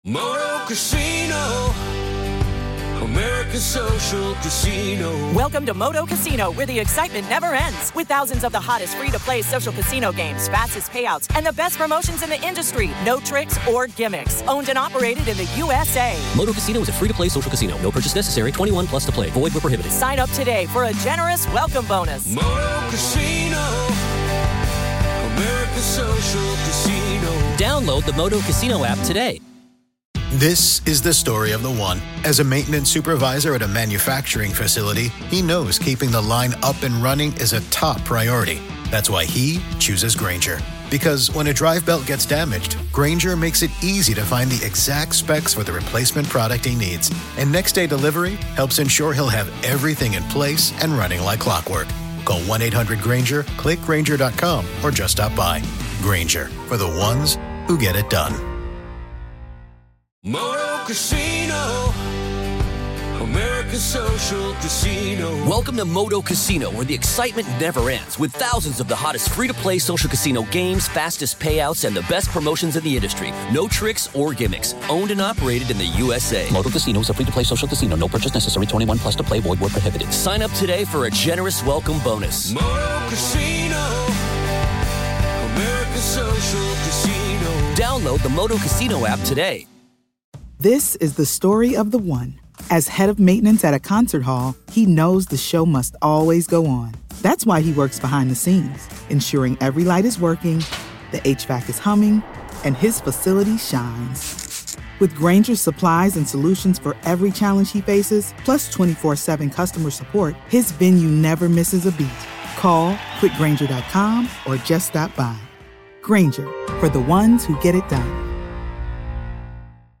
In this episode, we explore the dark legend of Goatman’s Bridge—where folklore, fear, and the unexplained collide in the heart of Texas. This is Part Two of our conversation.